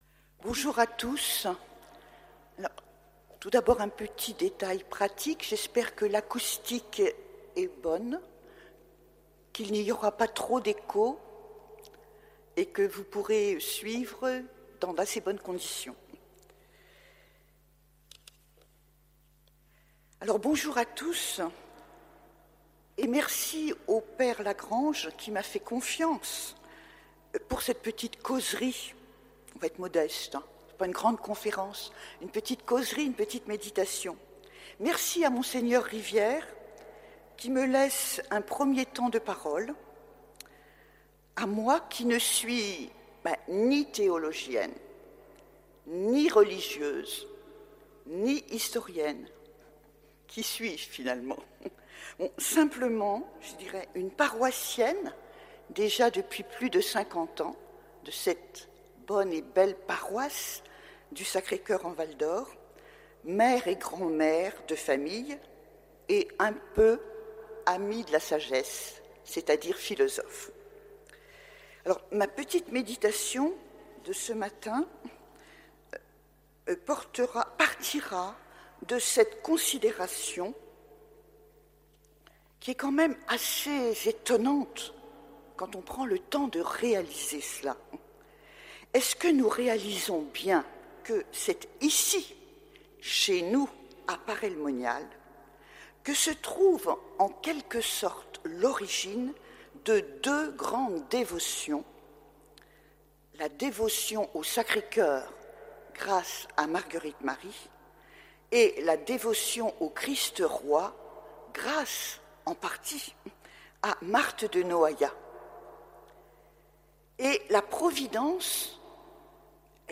Marthe de Noaillat et la fête du Christ Roi (conférence 2) - Sanctuaire du Sacré-Coeur Paray-Le-Monial